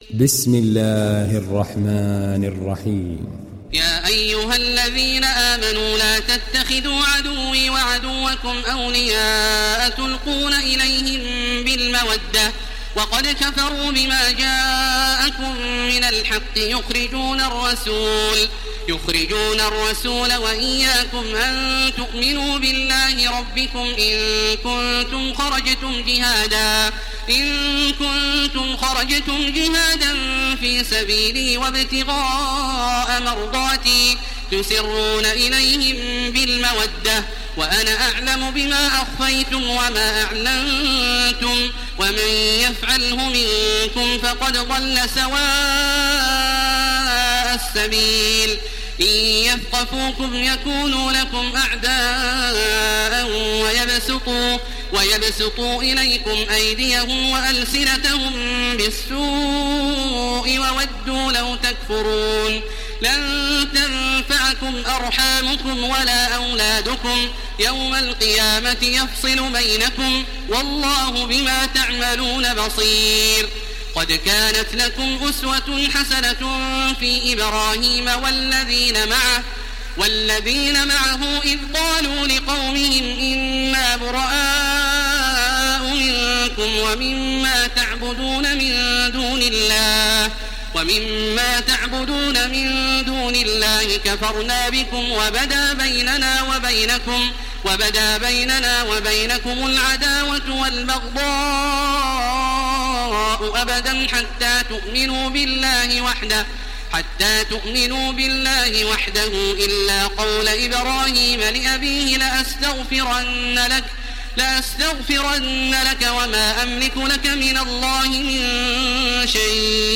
Télécharger Sourate Al Mumtahinah Taraweeh Makkah 1430